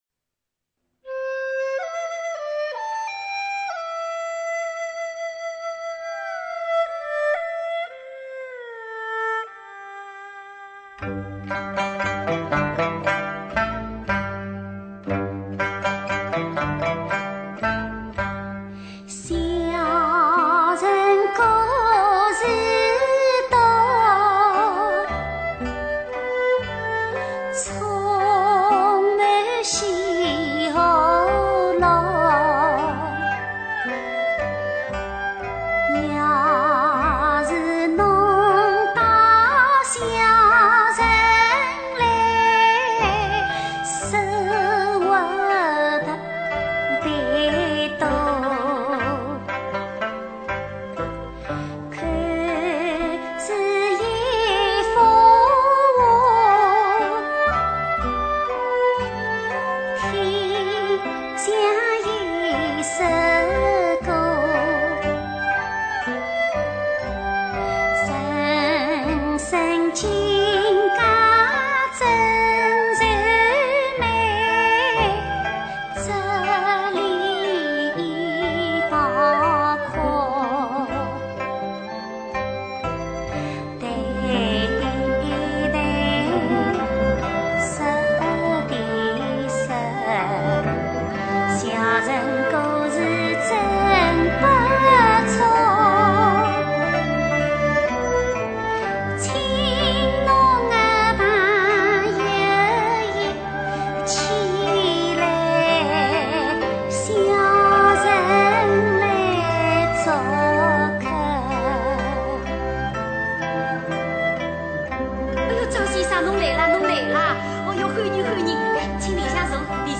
江苏民歌